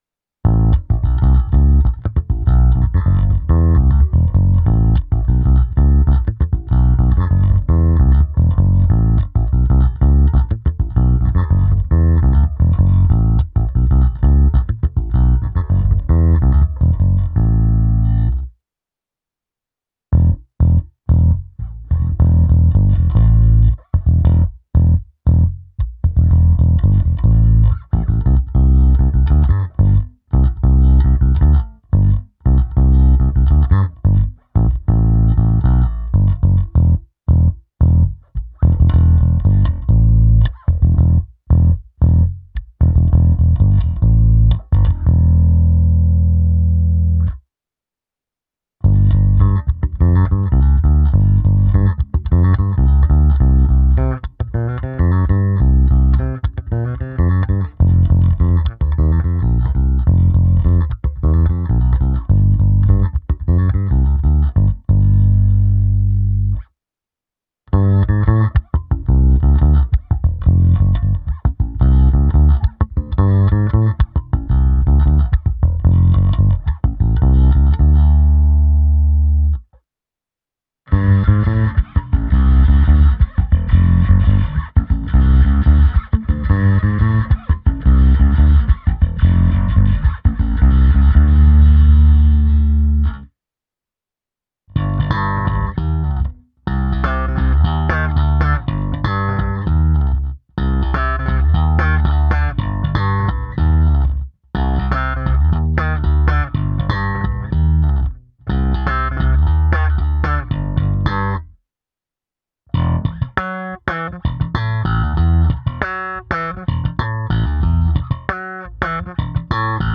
Hráno vždy nad snímačem.
Nahrávka se simulací aparátu s basy a výškami skoro naplno, samozřejmě s použitím kompresoru, použito v jedné části i zkreslení a na konci hra slapem.